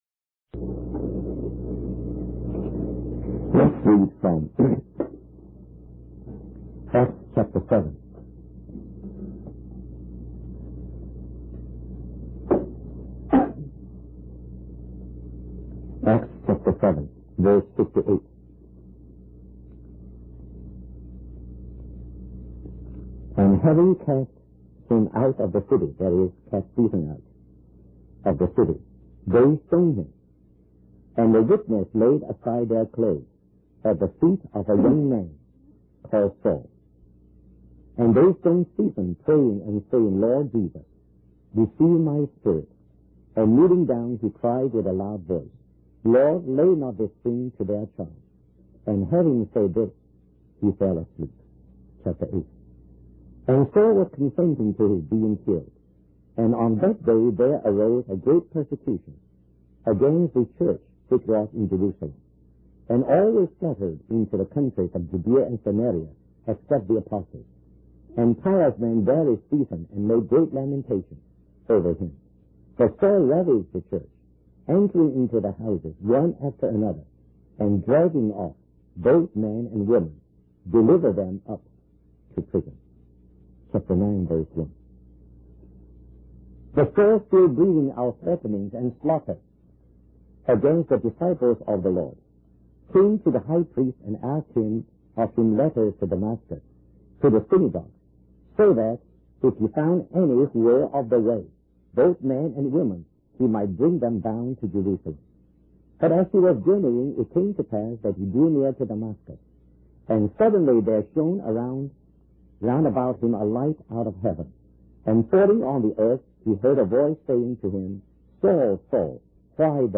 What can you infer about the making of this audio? We apologize for the poor quality audio